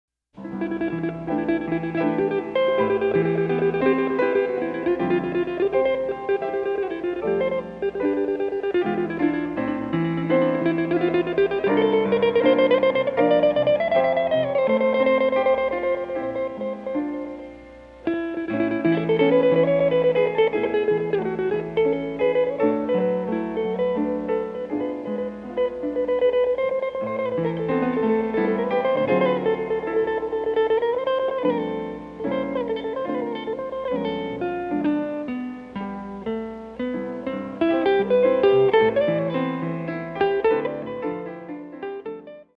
Guitar
Piano
Recorded live at Yellowfingers,